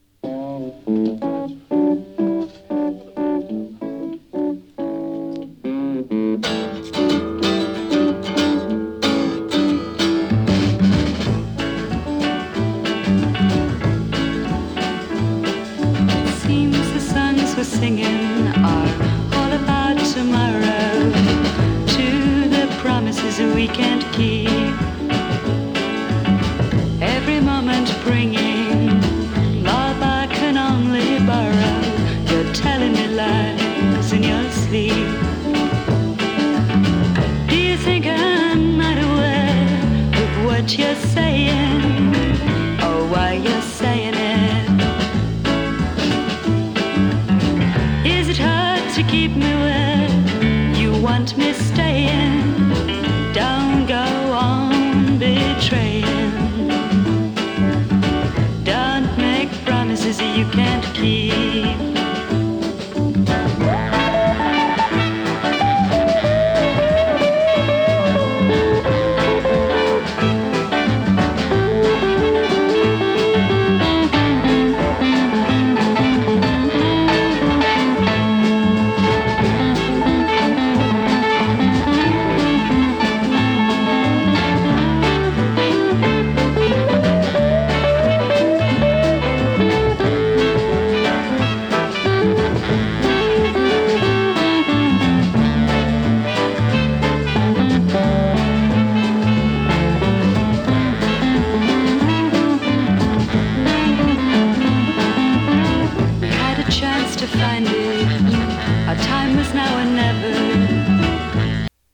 ソフトロック